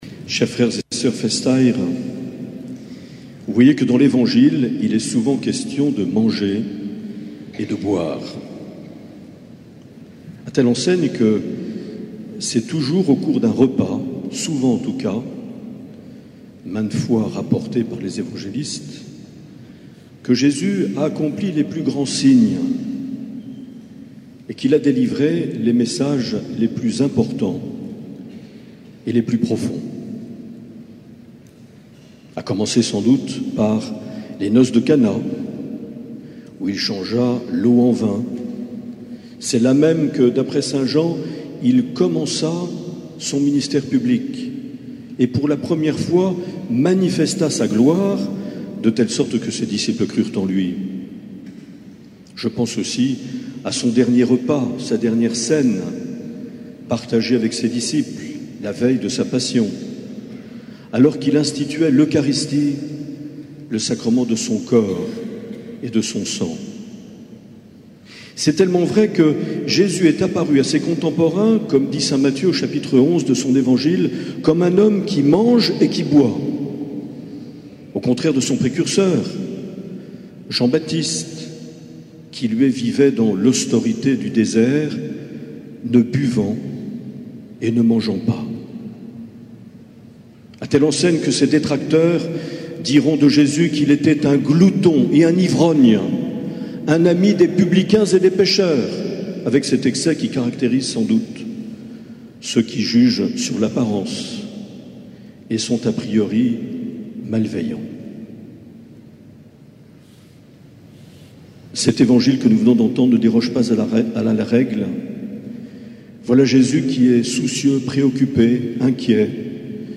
29 juillet 2018 - Saint André de Bayonne - Messe des fêtes
Les Homélies
Une émission présentée par Monseigneur Marc Aillet